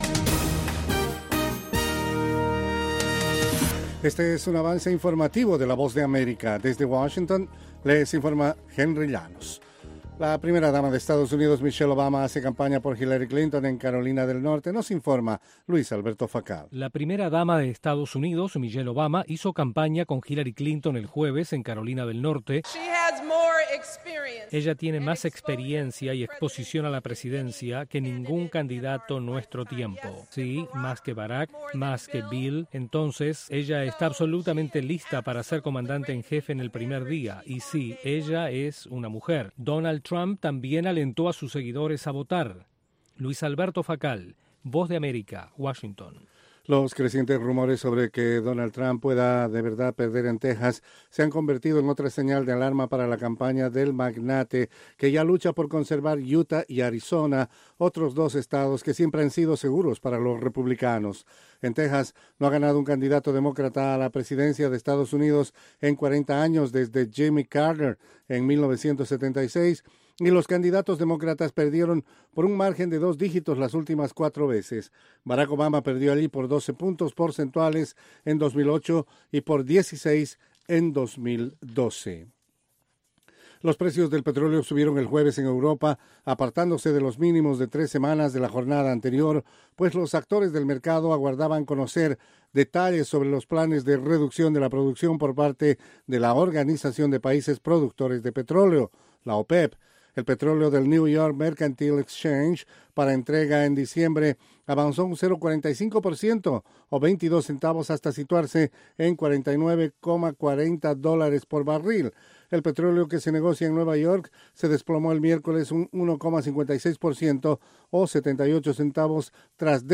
Capsula informativa de 5 minutos con el acontecer noticioso de Estados Unidos y el mundo.